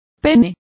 Complete with pronunciation of the translation of penis.